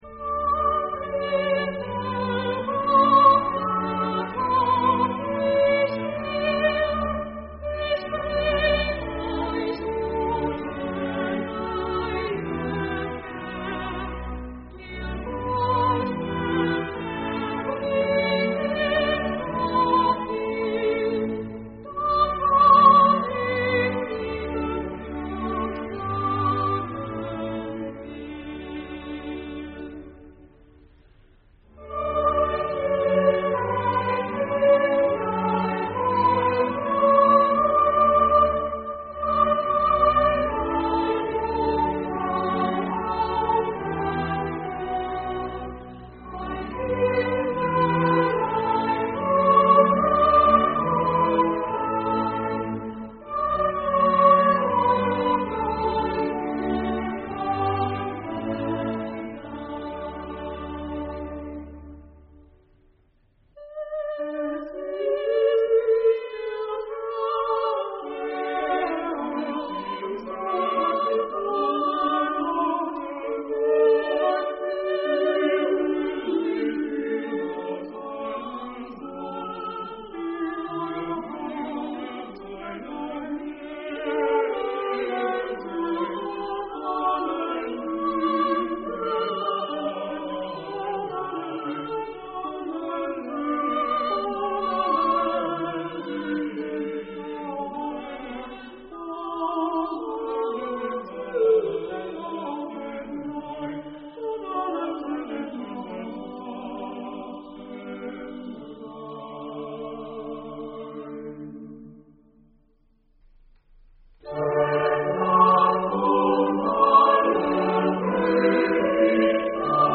Genre: Weihnachtsmusik.